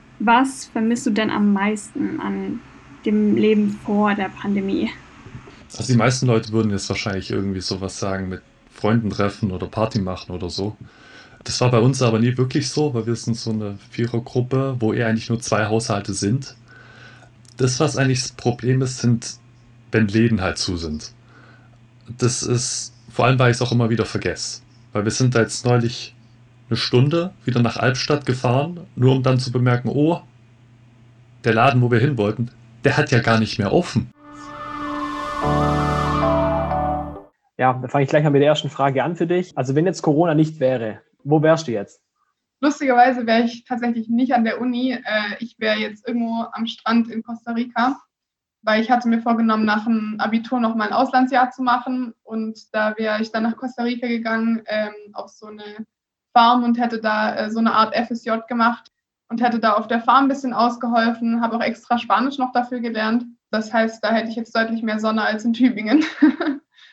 Studierende der Medienwissenschaft haben ihre Kommilitonen interviewt, um aus der Sicht der Studierenden zu berichten, denn das klassische Studieren lernen leider viele der Erstsemestler gerade nicht kennen.